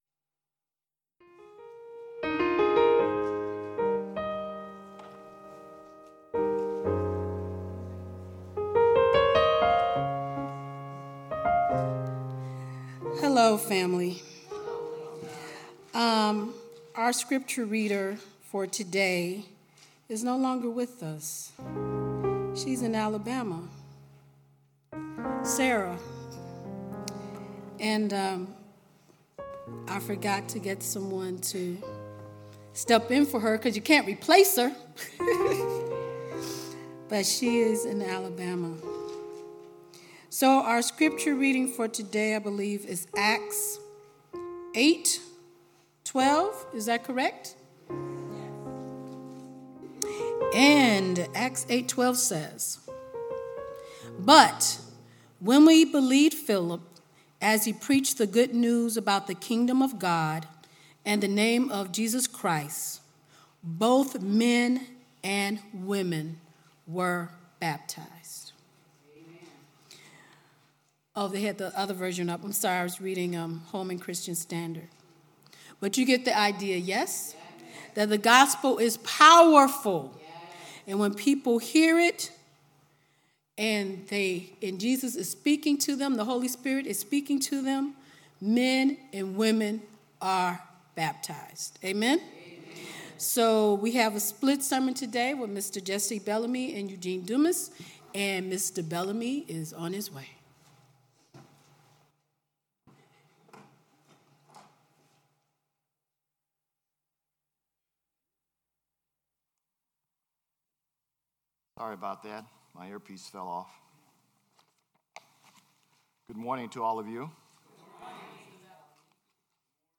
Worship Service 4/29/18